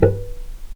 vc_pz-B4-pp.AIF